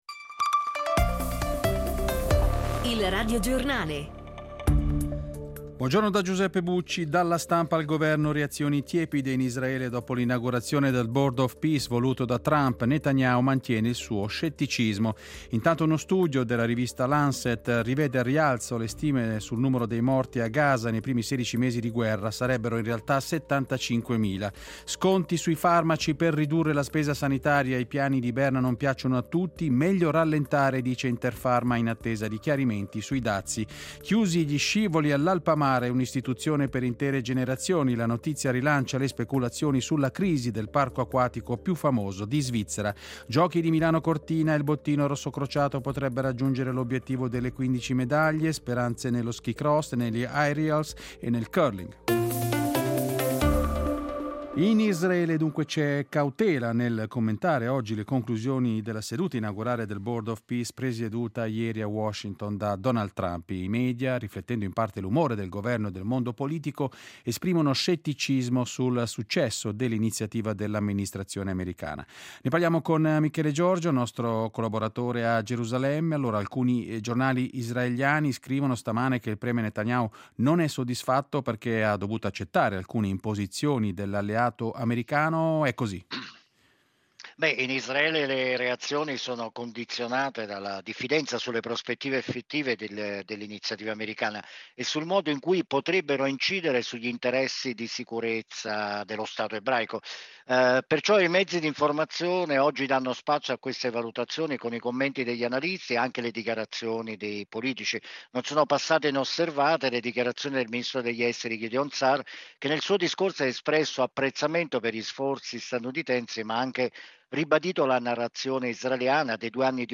Radiogiornale